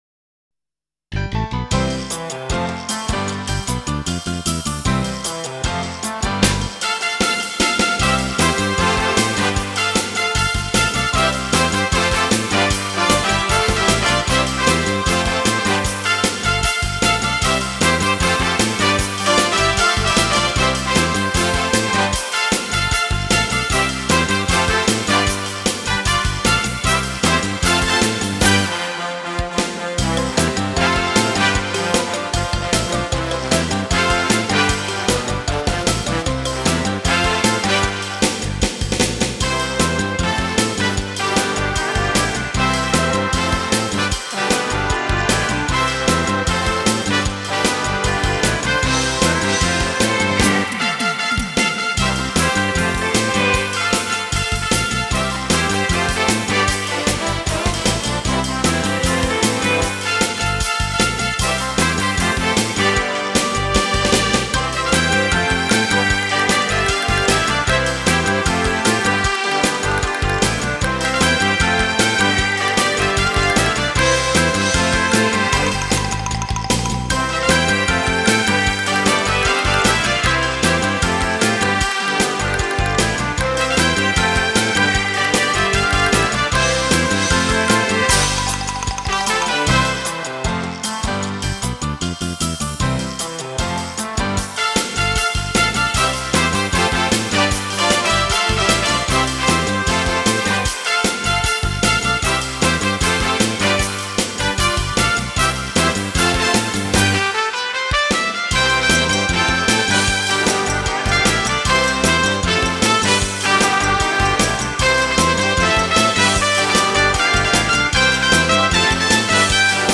【轻音乐】